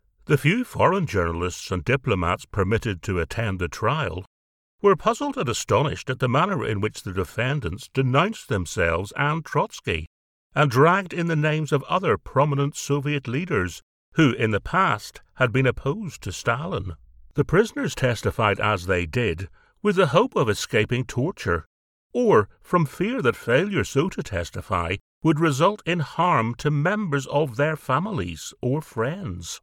Narration
I have a soft engaging Northern Irish Accent
Baritone , Masculine , Versatile . Commercial to Corporate , Conversational to Announcer . I have a deep, versatile, powerful voice, My voice can be thoughtful , authoritative , storytelling and funny . Confident and able to deliver with energy , humour and conviction .
RODE NT1A . TECPORTPRO , ISOLATED SOUND TREATED BOOTH. EDIT WITH AUDACITY